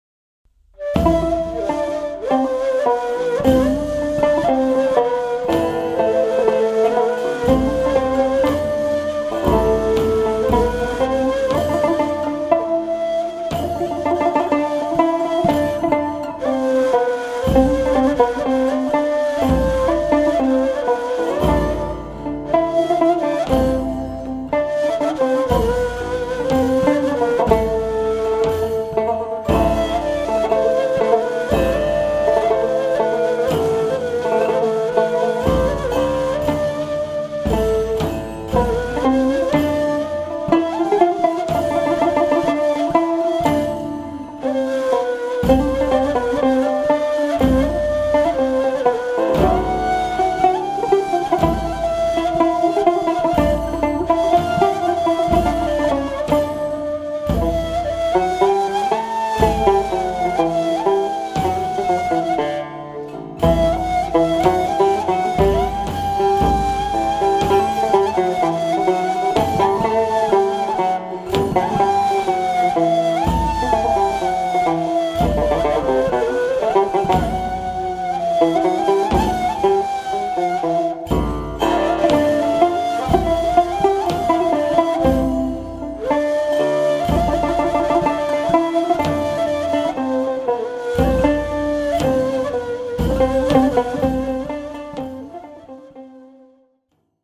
Genre: Turkish & Ottoman Classical.
Recorded at Aria Studios, Istanbul
tanbur
ney